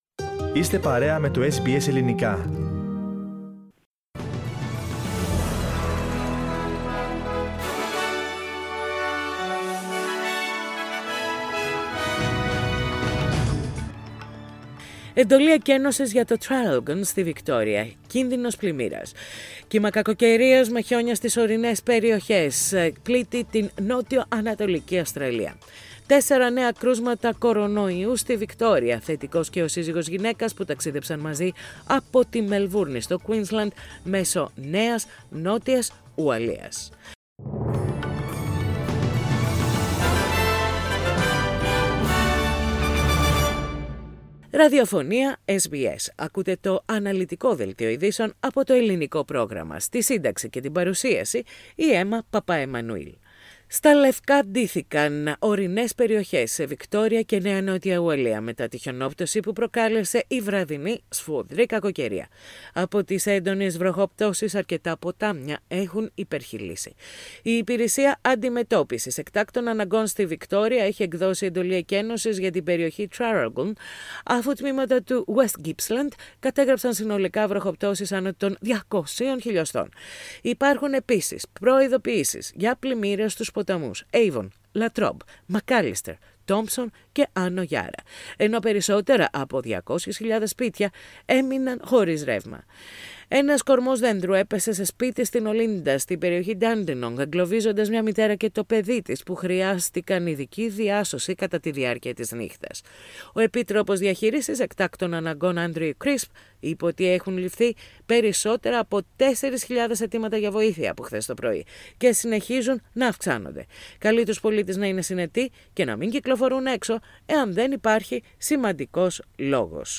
News in Greek - Thursday 10.6.21